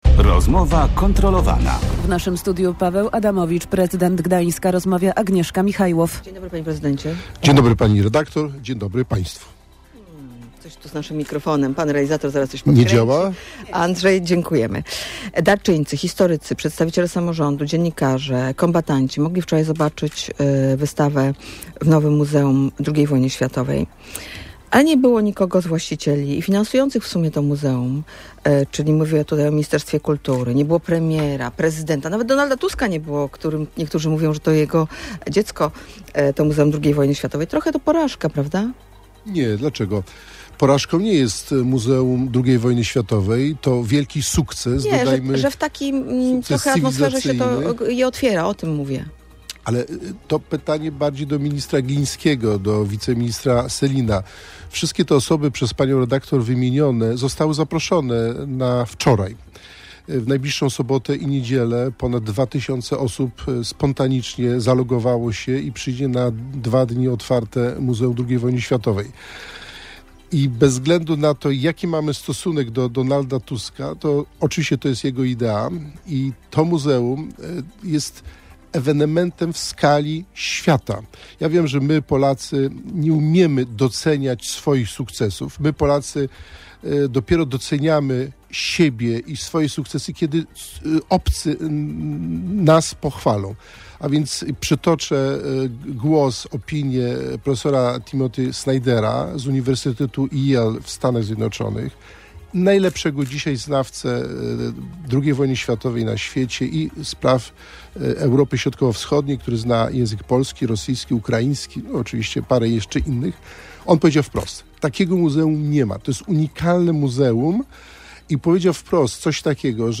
– To złamanie kultury śródziemnomorskiej i zbliżenie Polski do Białorusi – tak fakt, że planowane zmiany w ordynacji wyborczej miałby obowiązywać „wstecz” komentuje w Radiu Gdańsk Paweł Adamowicz.